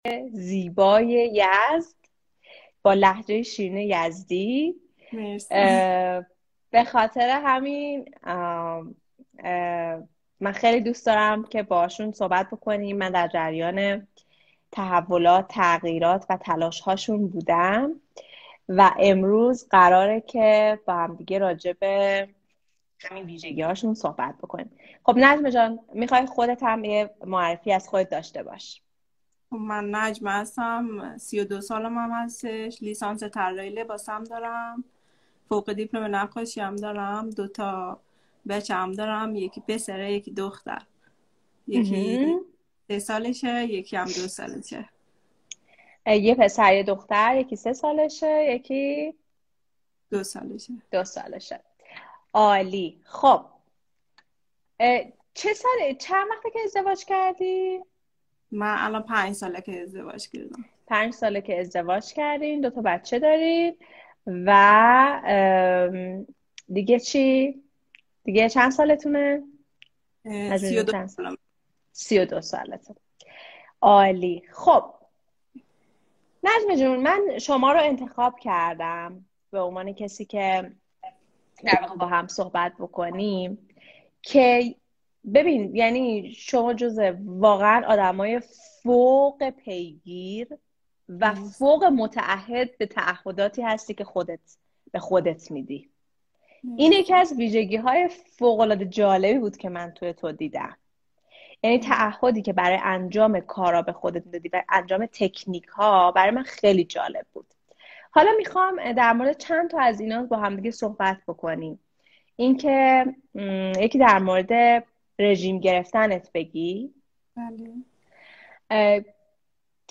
مصاحبه با مادر بالنده 4